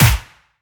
Verby Steel Snare Drum Sample A# Key 105.wav
Royality free acoustic snare tuned to the A# note. Loudest frequency: 3314Hz
verby-steel-snare-drum-sample-a-sharp-key-105-Sb8.ogg